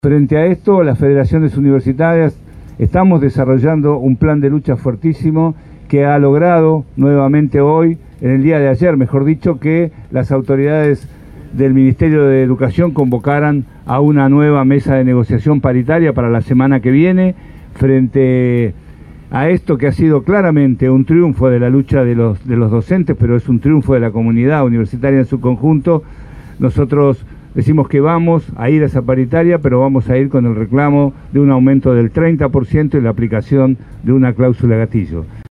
Los gremios universitarios dictaron clases públicas frente a la Casa Rosada como medida de protesta al recorte de presupuesto y a una paritaria salarial estancada.